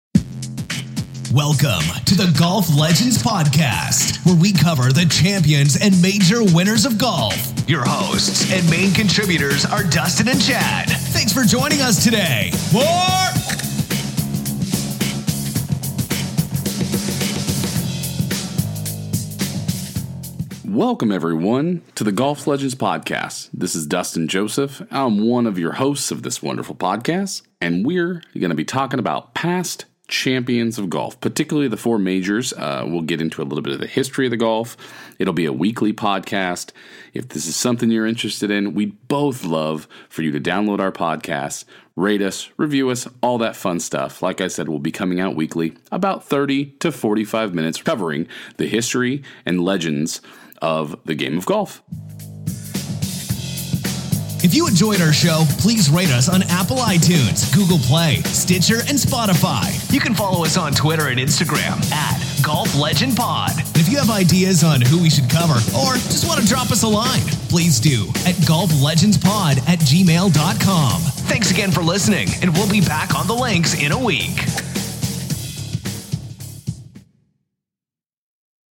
Two amateur golfers who love the history and stories of the game.
Trailer: